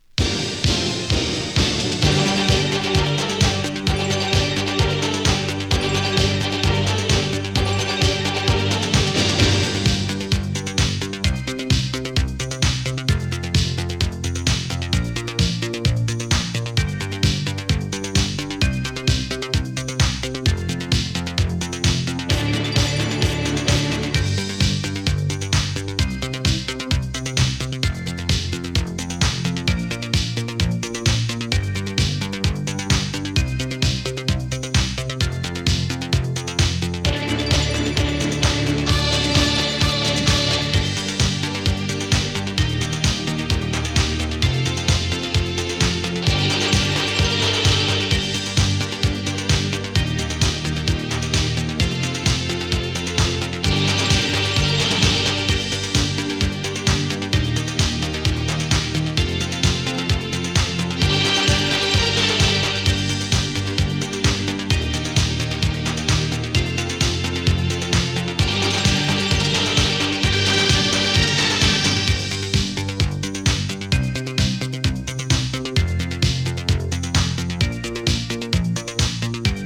ユーロビート
♪Instrumental (5.39)♪